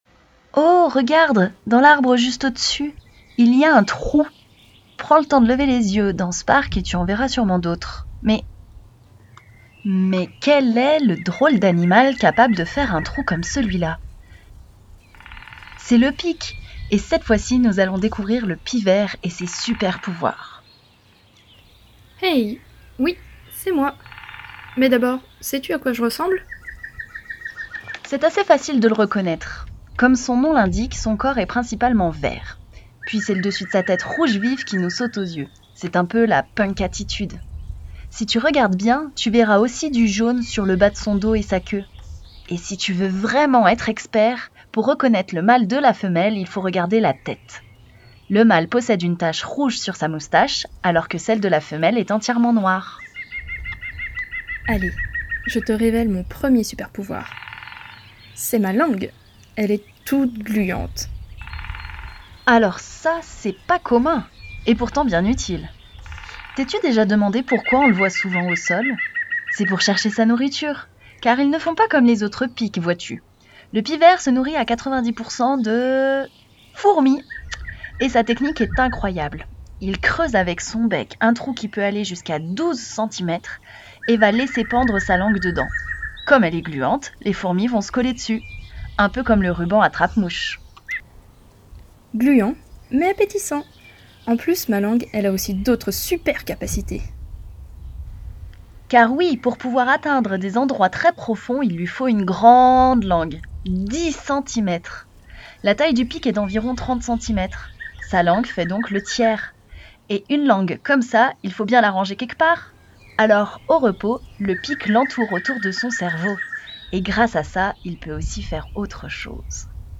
picvert.mp3